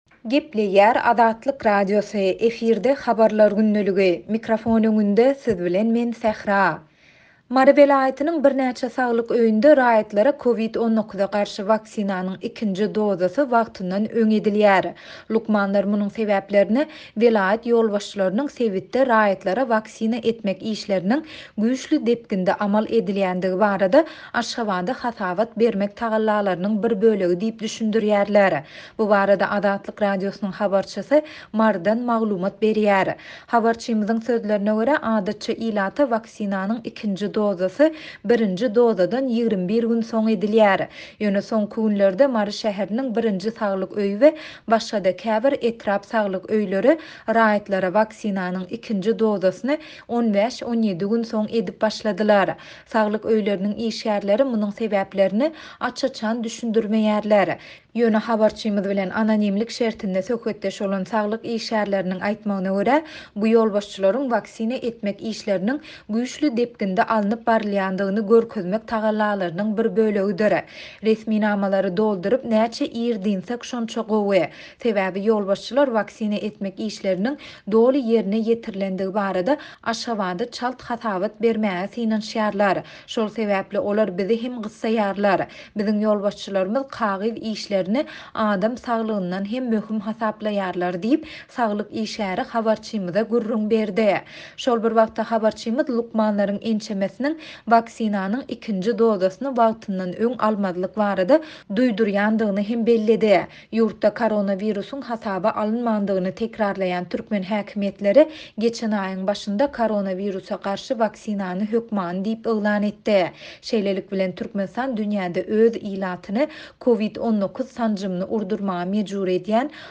Bu barada Azatlyk Radiosynyň habarçysy Marydan maglumat berýär.